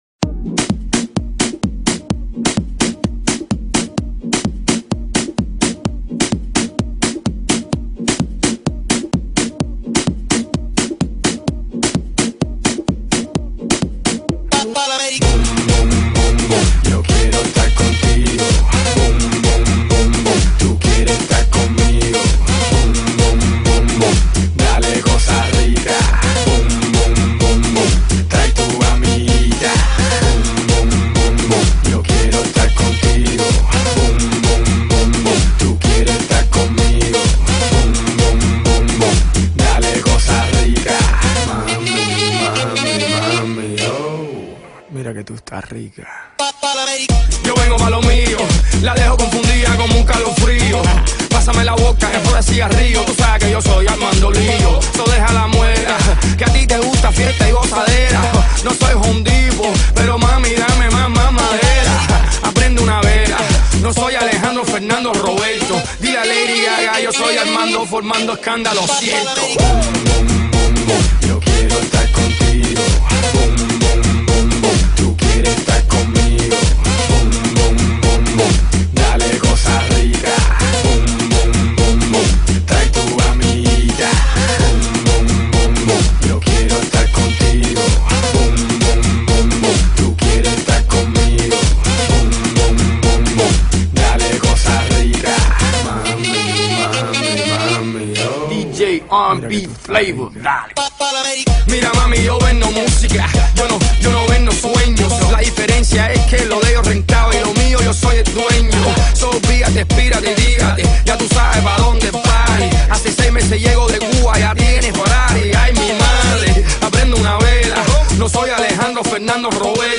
بیس دار تند
بیس دار شاد